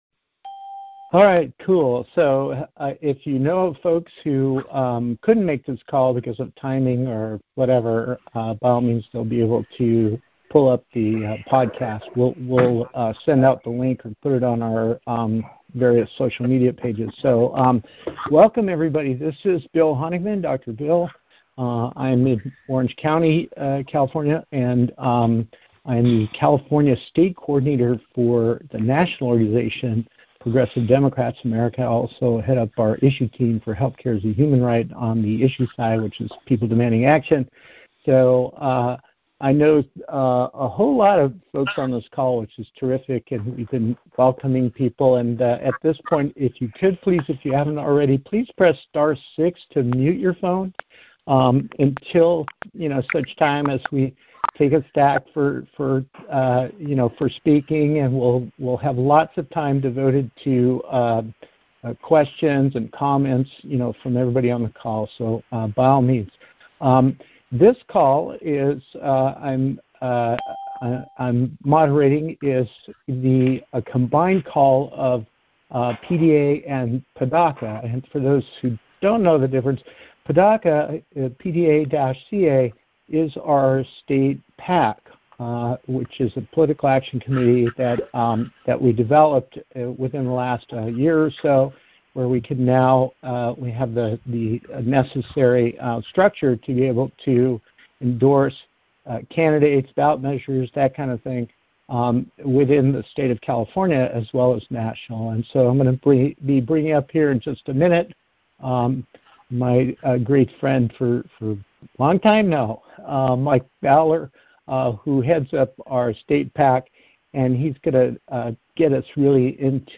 PDA / PDA-CA ADEM teleconference 12/20/2018